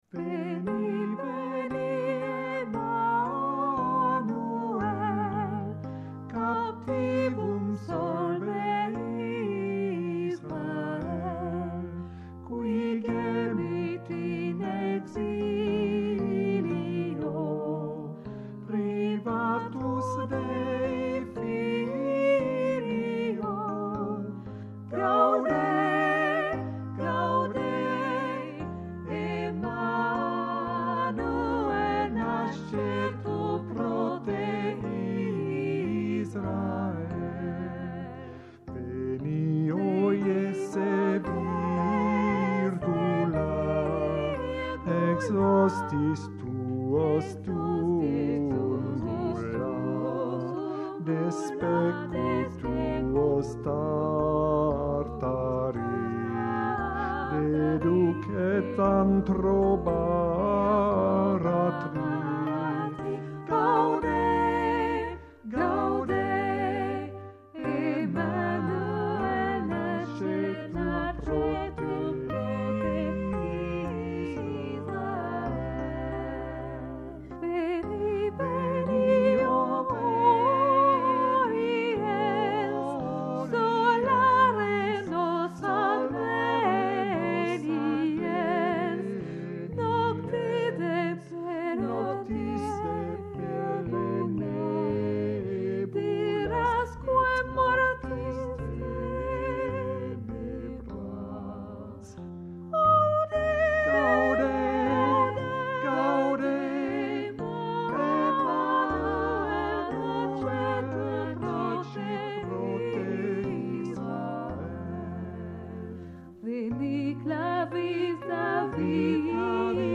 Noëls
enregistrement mp3 - travail 4 Voix + accompagnement'